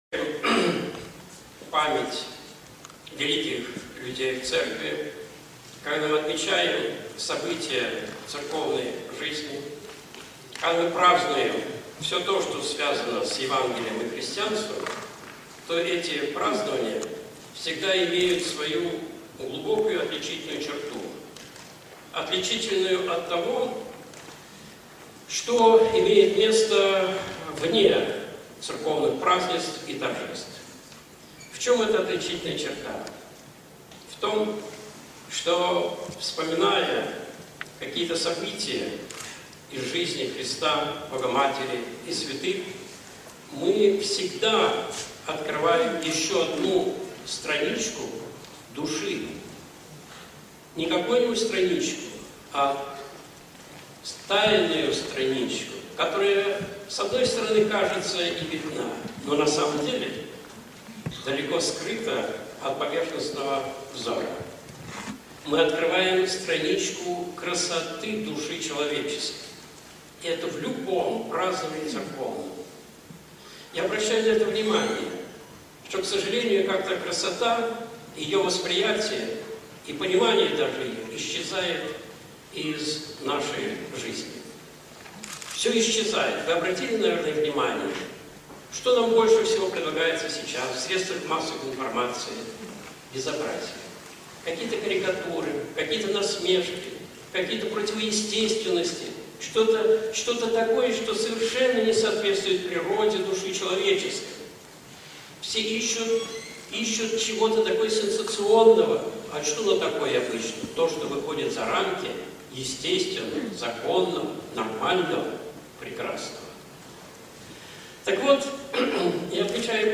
IV Свято-Игнатиевские чтения (Ставрополь, 2011)
Видеолекции протоиерея Алексея Осипова